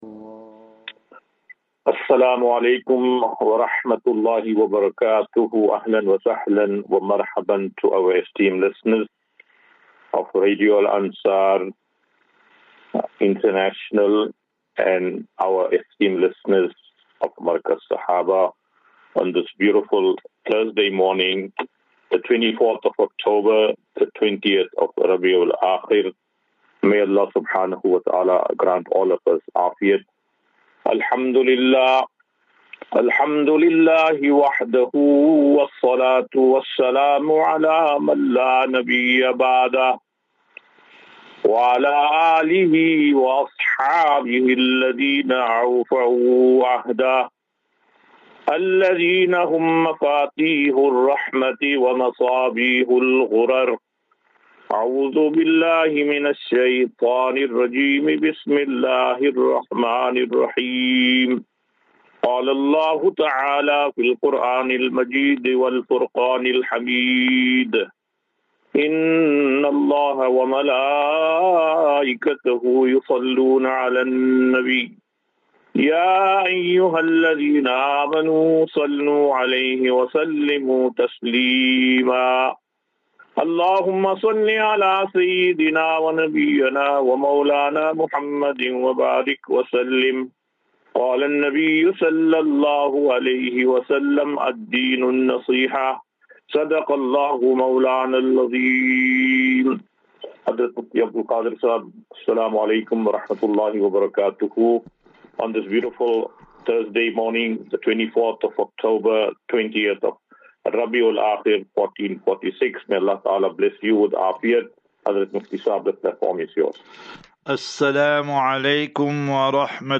24 Oct 24 October 2024 - Assafinatu - Illal - Jannah. QnA.